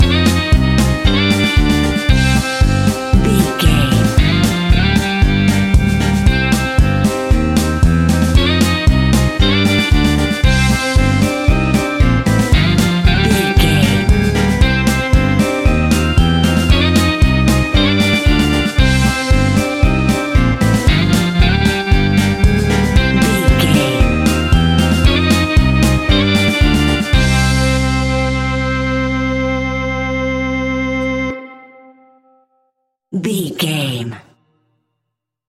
Aeolian/Minor
scary
ominous
dark
eerie
energetic
groovy
drums
electric guitar
bass guitar
piano
synthesiser
Horror Synths